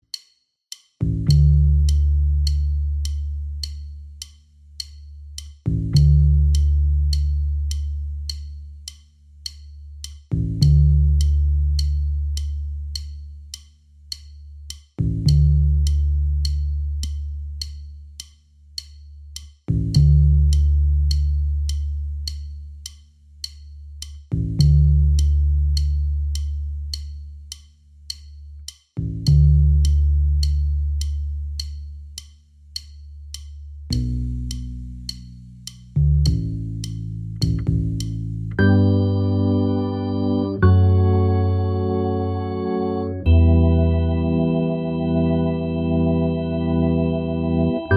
Minus All Guitars R'n'B / Hip Hop 3:11 Buy £1.50